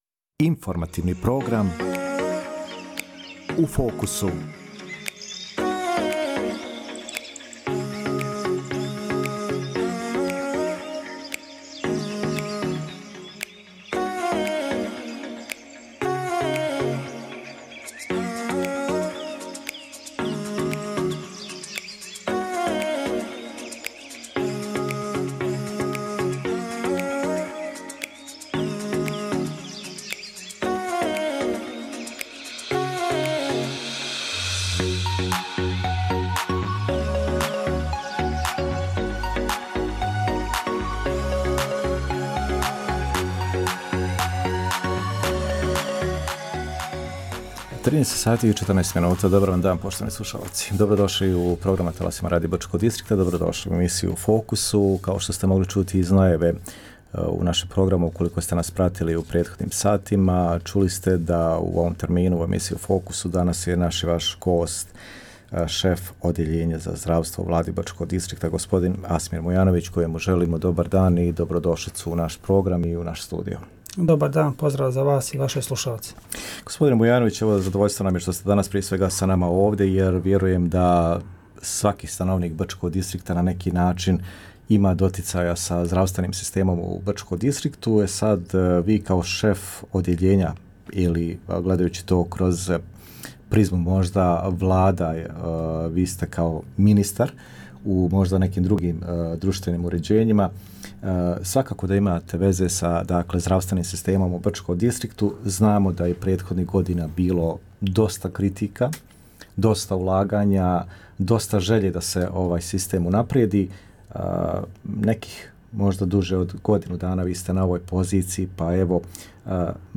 U emisiji “U fokusu”, razgovarali smo sa šefom Odjeljenja za zdravstvo i ostale usluge Vlade Brčko distrikta BiH Asmirom Mujanovićem o planovima, nabavkama, kapitalnim ulaganjima i ostalim budućim aktivnostima ovog odjeljenja.